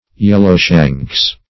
yellowshanks - definition of yellowshanks - synonyms, pronunciation, spelling from Free Dictionary
Yellowshanks \Yel"low*shanks`\, Yellowshins \Yel"low*shins`\, n.
yellowshanks.mp3